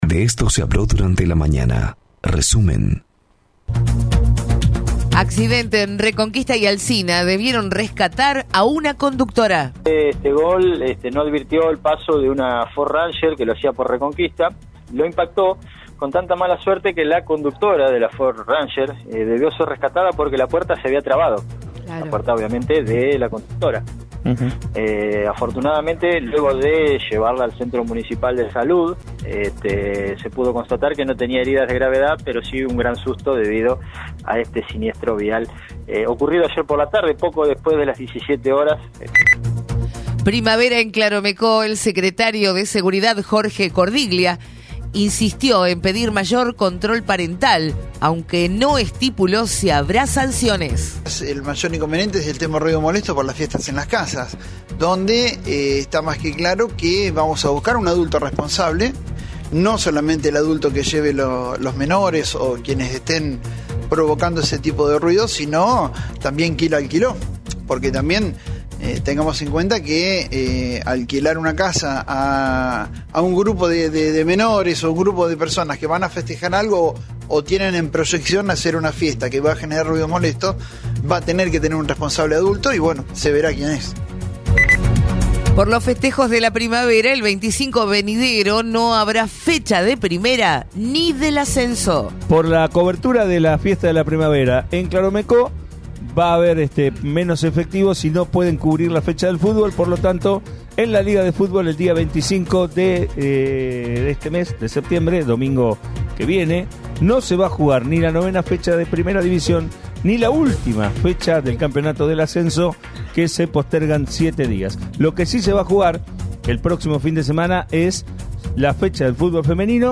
Escuchá los principales temas noticiosos de la mañana en nuestro resumen de Radio 3 95.7.